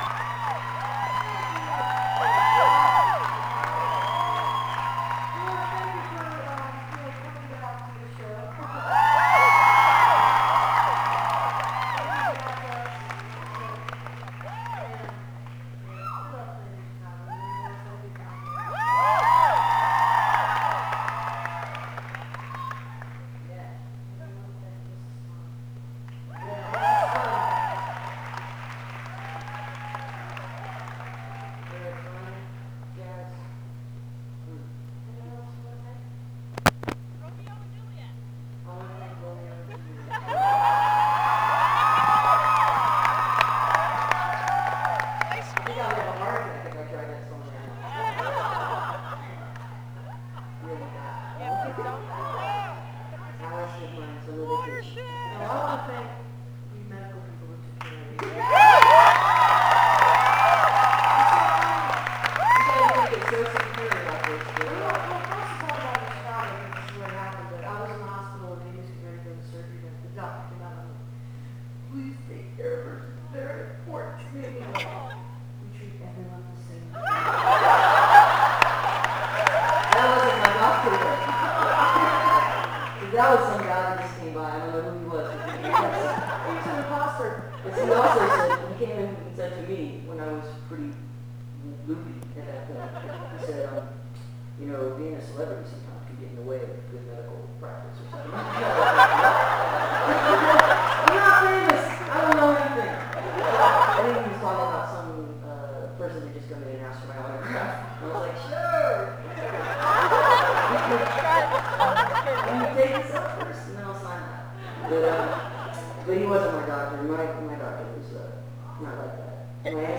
(acoustic duo show)
16. talking with the crowd (2:23)